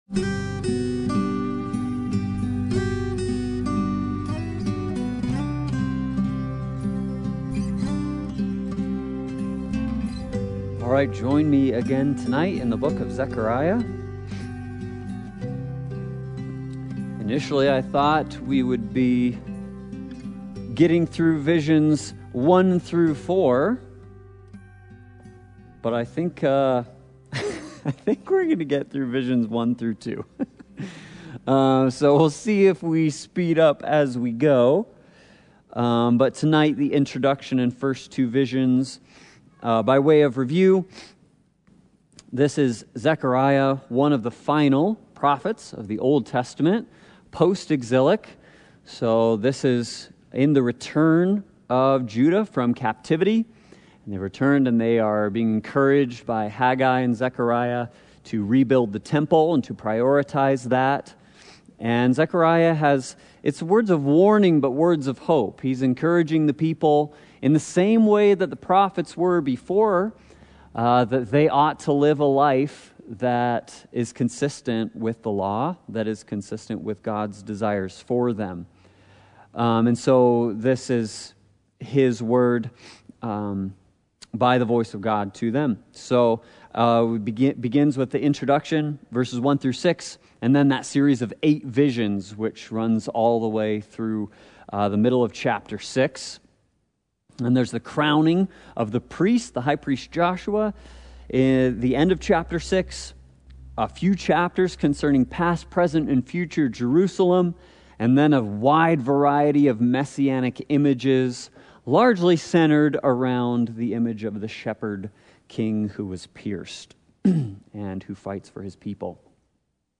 The Book of the Twelve Service Type: Sunday Bible Study « Run with Endurance